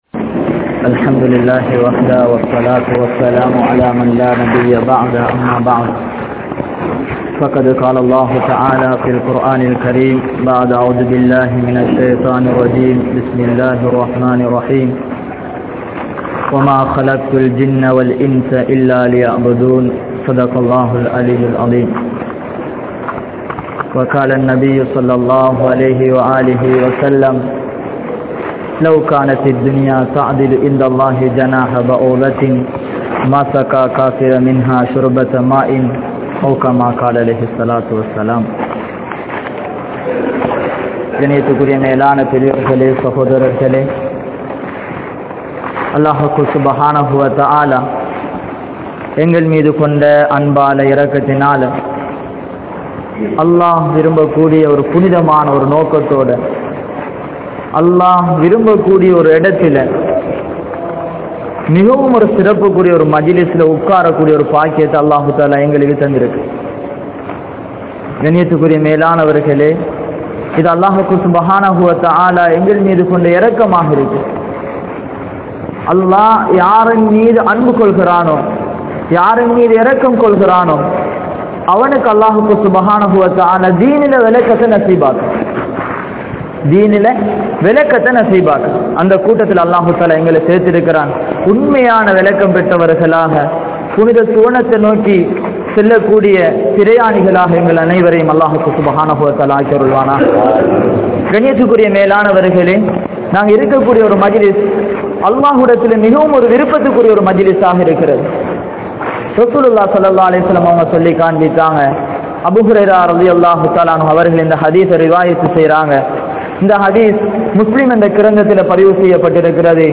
Allah`vin Sakthi (அல்லாஹ்வின் சக்தி) | Audio Bayans | All Ceylon Muslim Youth Community | Addalaichenai